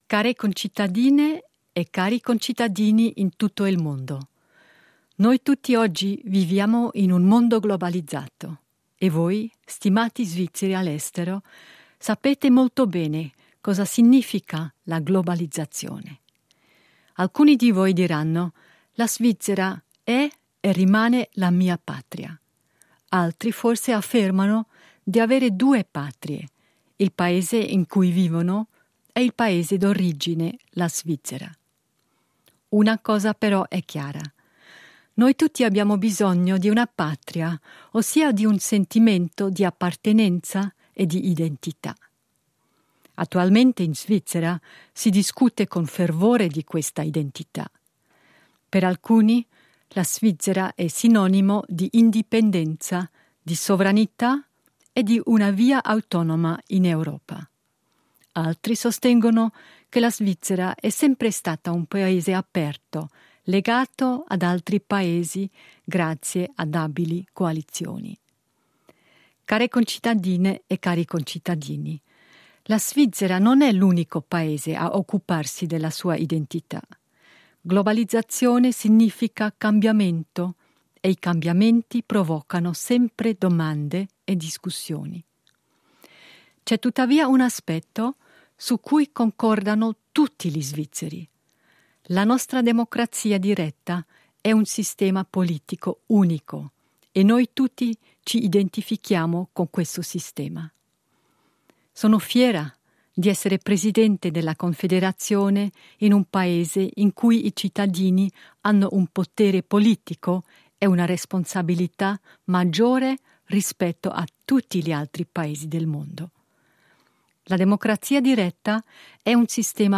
Discorso della presidente della Confederazione Simonetta Sommaruga